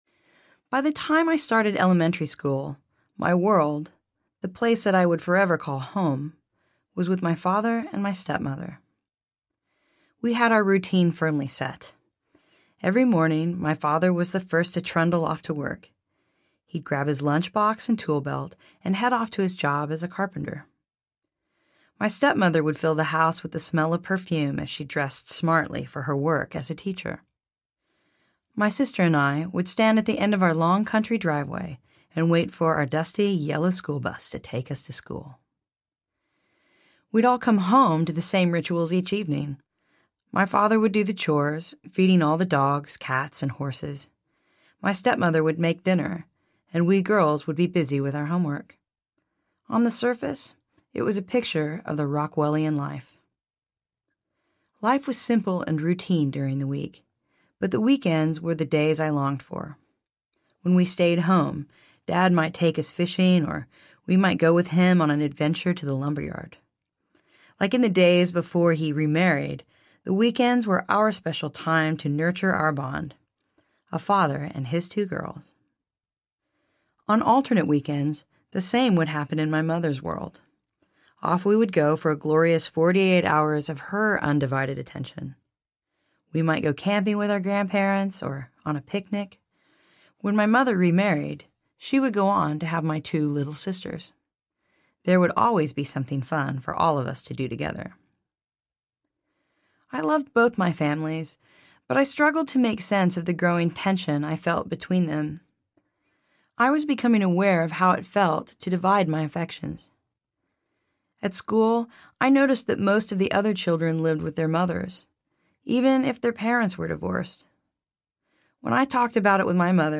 Facing the Music Audiobook
Narrator